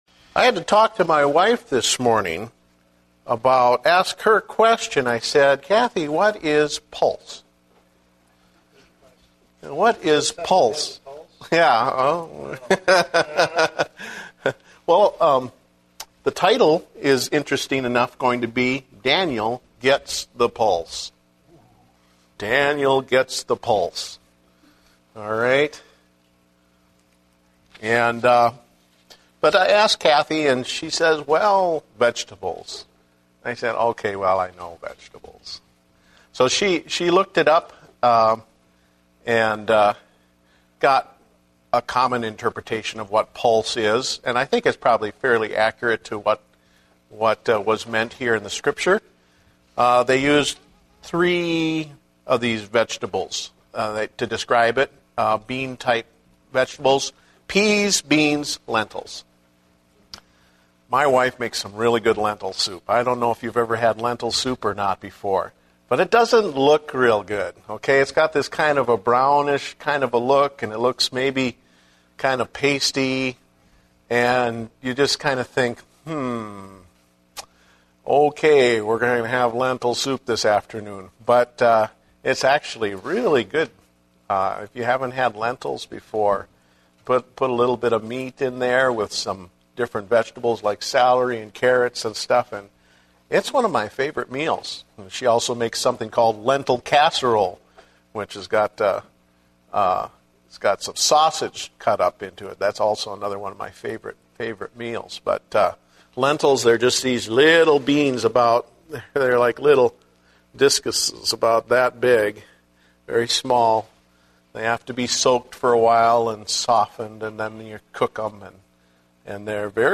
Date: September 12, 2010 (Adult Sunday School)